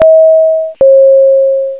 cabinalert.wav